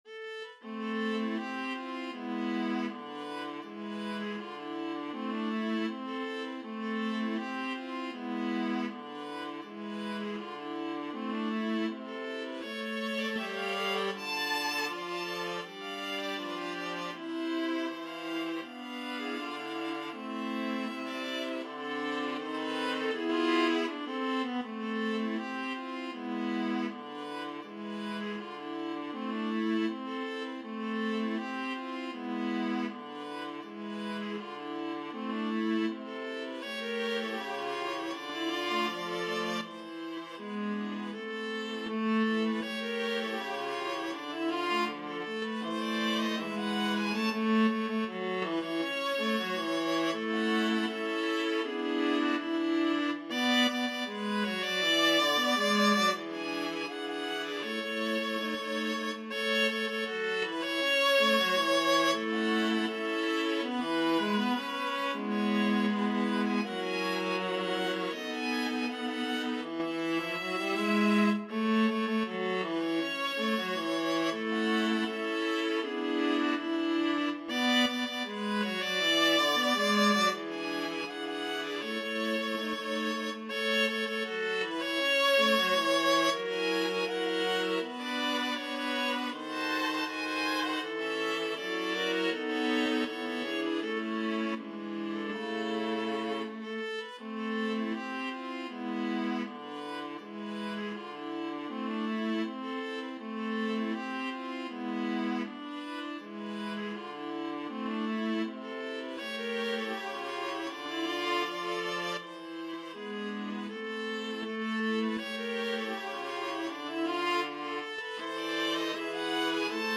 A beautiful tango melody in an A-B-A form.
2/4 (View more 2/4 Music)
Grazioso =80
World (View more World Viola Ensemble Music)